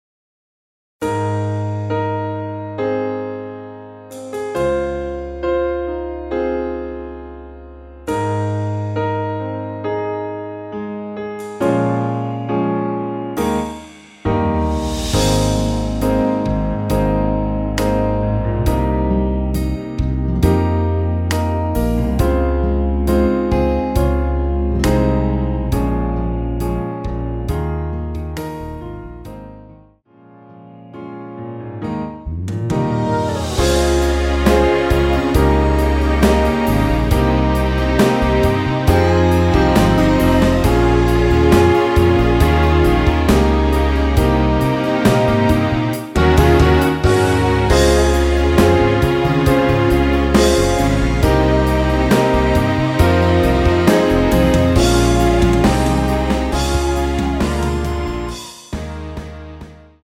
원키에서(+2)올린 2절 부터 시작 하게 편곡 하였습니다.(미리듣기 참조)
Ab
앞부분30초, 뒷부분30초씩 편집해서 올려 드리고 있습니다.
중간에 음이 끈어지고 다시 나오는 이유는